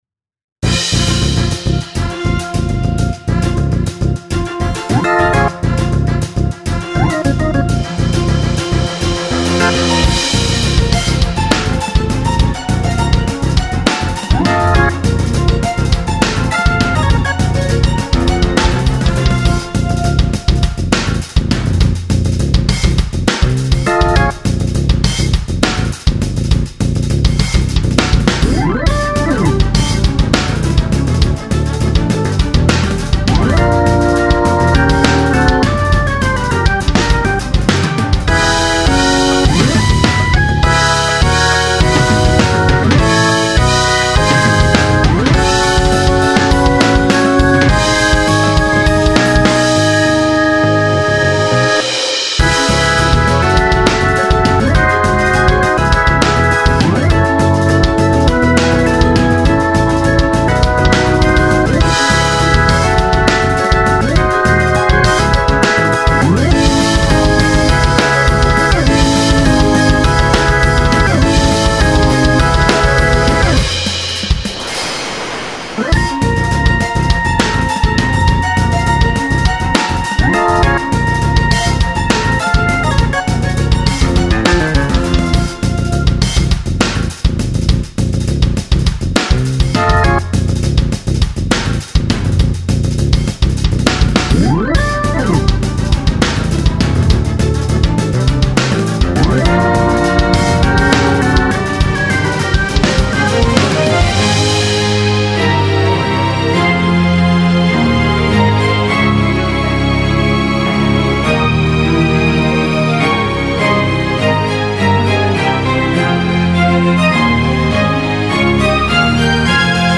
Dr/Ba/Org/Harp/Melody/Cho
+Strings+Cello+Ocarina
+E.GUITAR